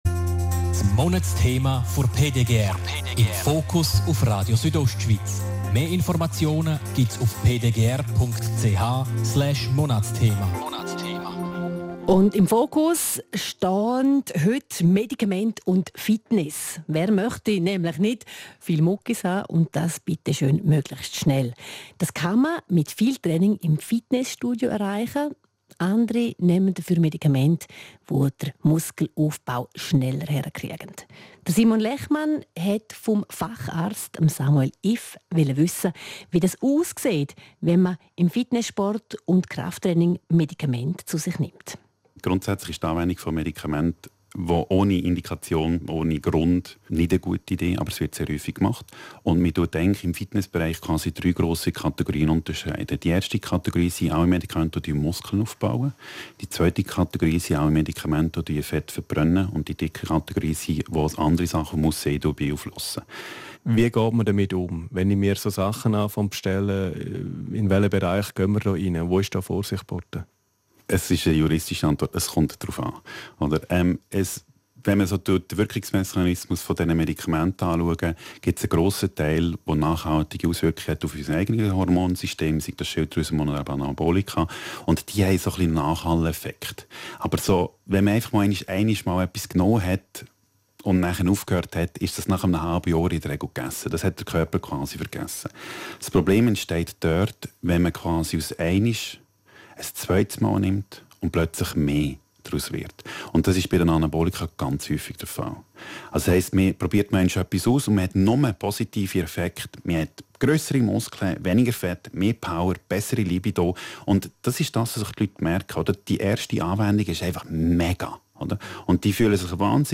Interview Radio Südostschweiz